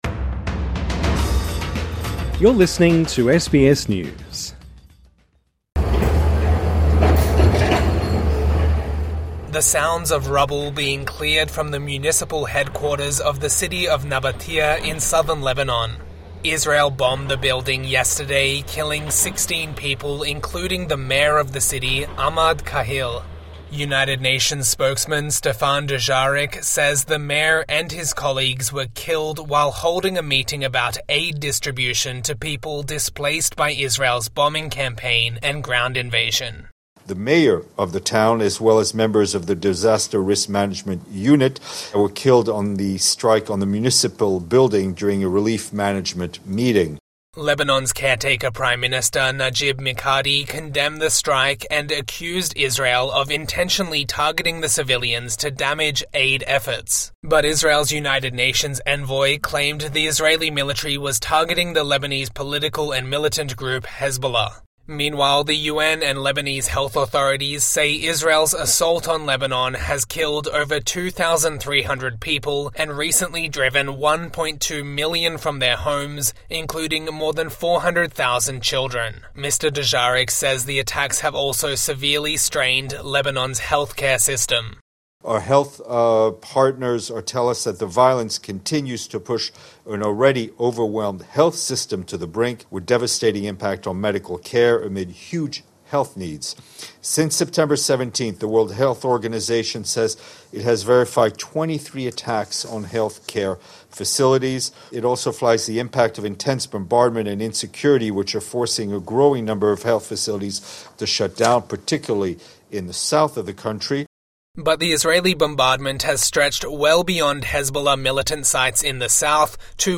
TRANSCRIPT The sounds of rubble being cleared from the municipal headquarters of the city of Nabatieh in southern Lebanon.